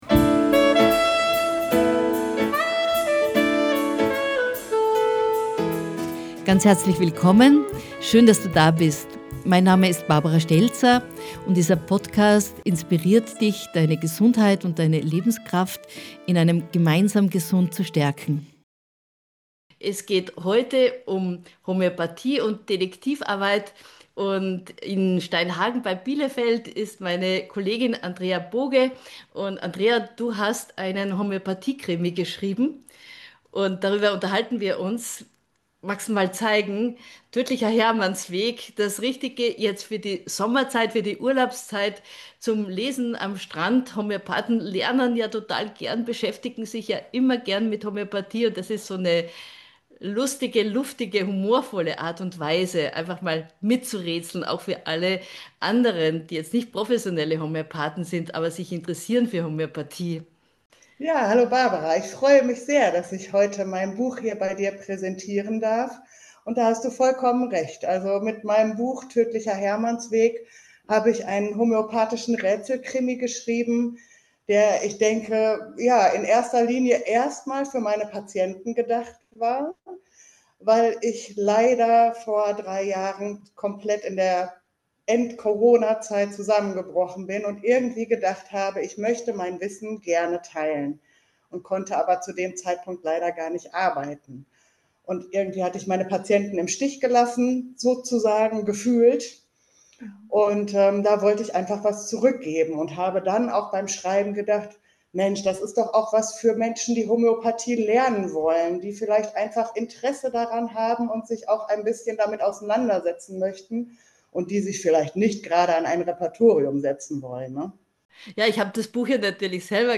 Ein unterhaltsames, lehrreiches Gespräch für alle, die Homöopathie einmal aus einer ganz neuen Perspektive erleben möchten!